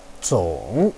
zong3.wav